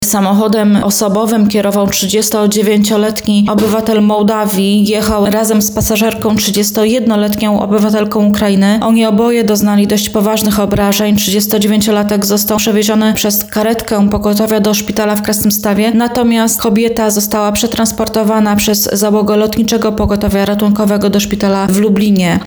O skutkach wypadku mówi